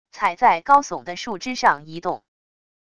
踩在高耸的树枝上移动wav音频